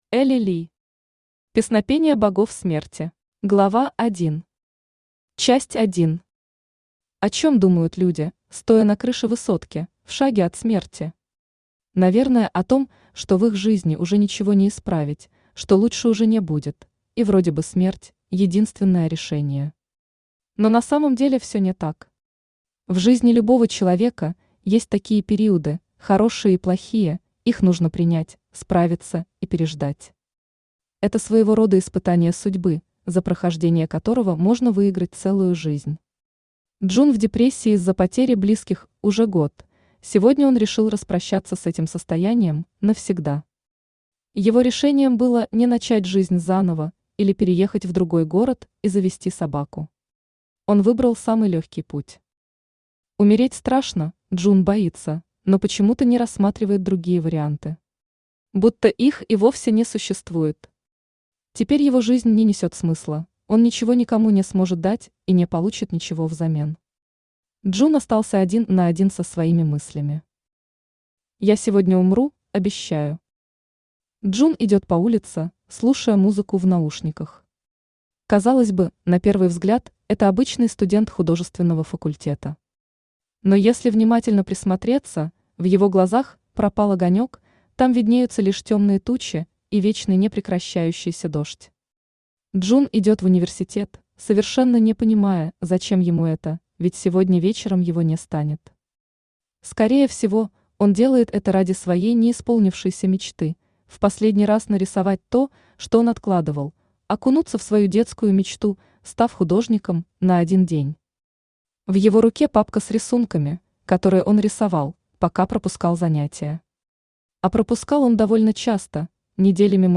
Аудиокнига Песнопения Богов Смерти | Библиотека аудиокниг
Aудиокнига Песнопения Богов Смерти Автор Эли Ли Читает аудиокнигу Авточтец ЛитРес.